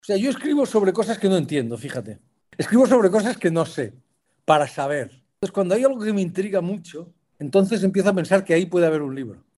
El pasado 19 de abril, los célebres autores Javier Cercas y Lorenzo Silva compartieron con los lectores y lectoras ciegos de los más de 30 Clubes de Lectura (braille y sonoro) que la ONCE tiene en el país una tarde en la que, ambos escritores conversaron y compartieron sus experiencias literarias en el ya tradicional encuentro literario online con motivo del Día del Libro.